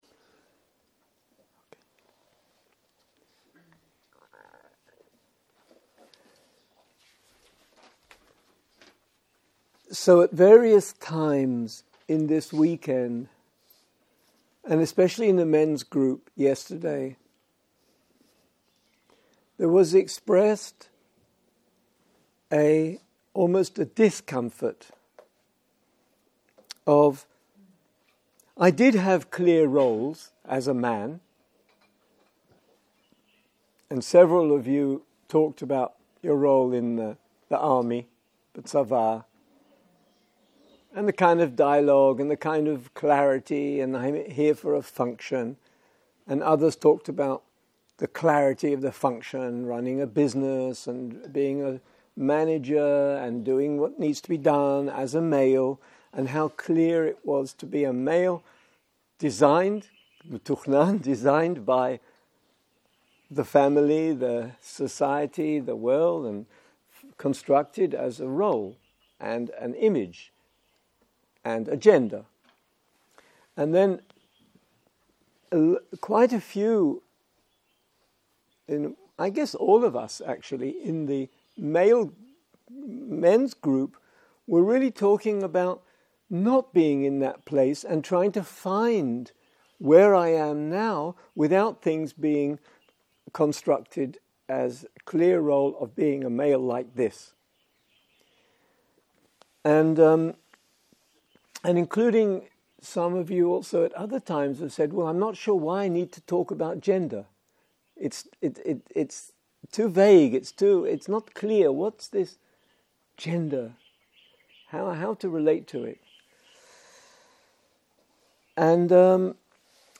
בוקר - שיחת דהרמה